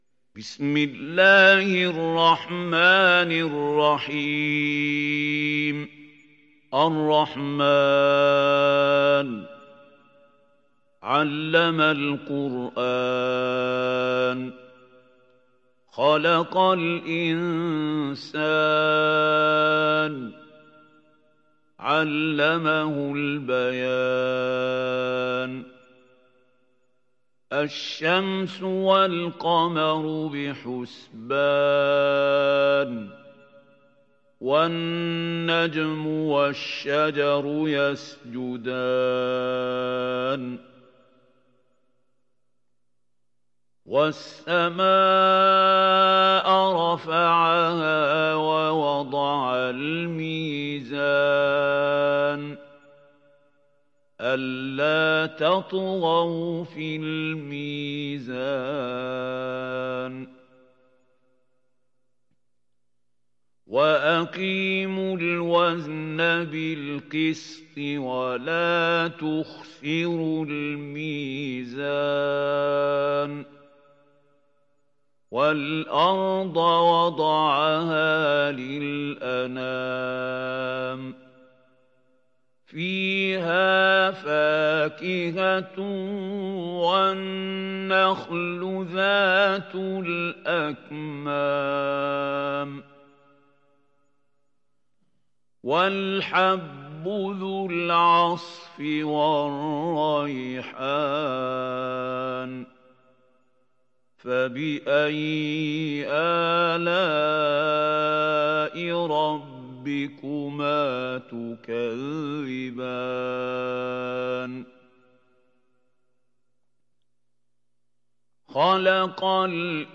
Sourate Ar Rahman Télécharger mp3 Mahmoud Khalil Al Hussary Riwayat Hafs an Assim, Téléchargez le Coran et écoutez les liens directs complets mp3